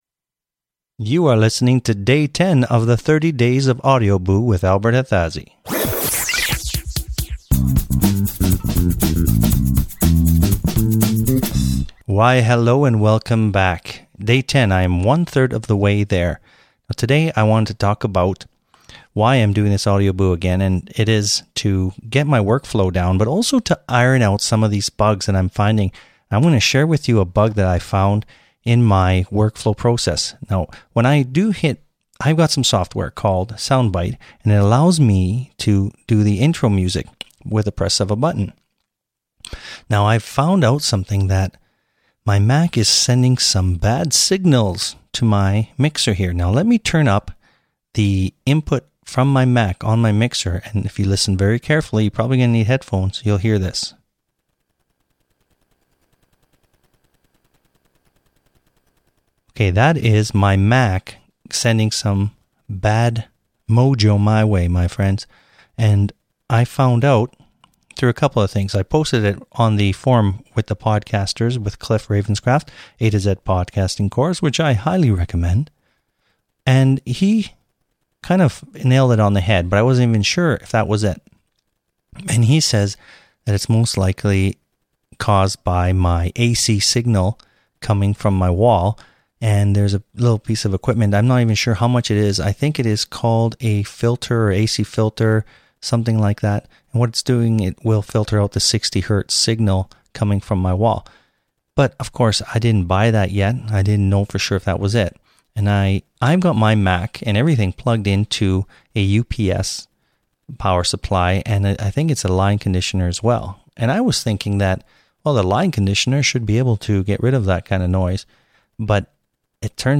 It's a good thing I am ironing out the wrinkles of my soon to be announced podcast because I have found some issues with the sound. In this episode I talk about the bad mojo and what is causing it.